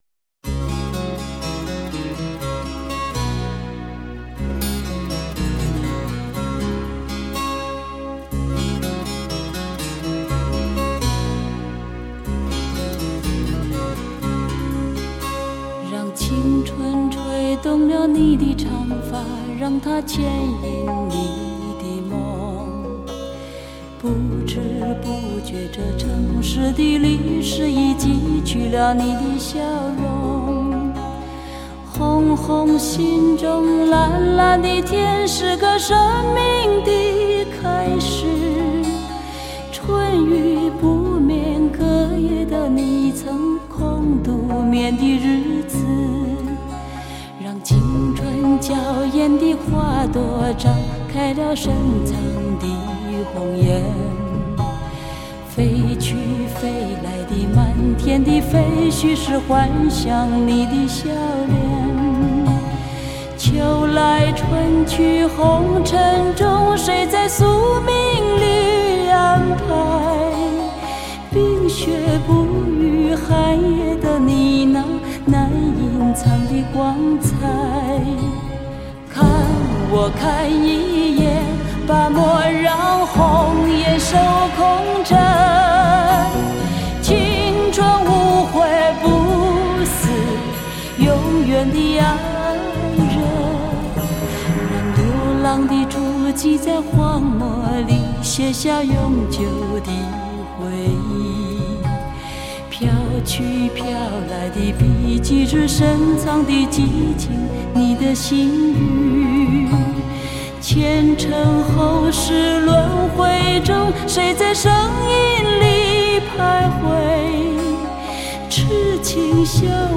LP黑胶 精装10碟 HI-FI音质